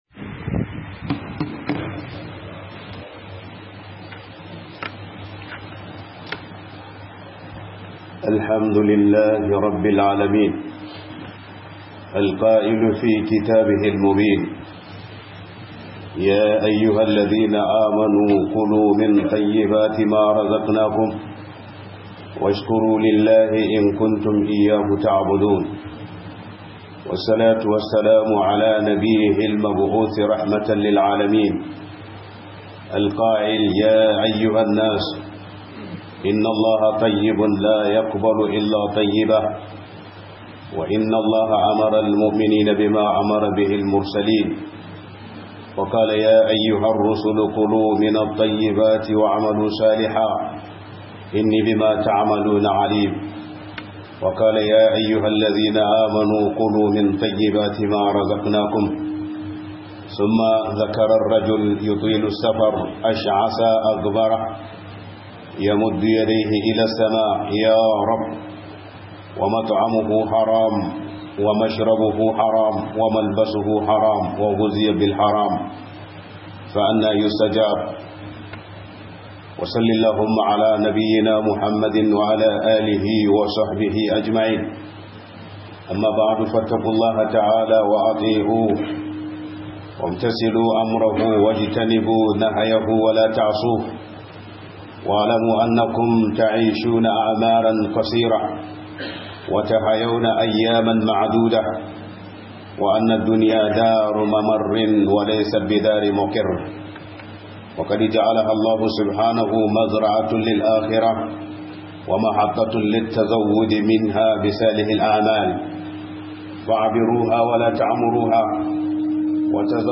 SON DUNIYA YA MAKANTAR DAMU - 2025-11-21 - HUDUBA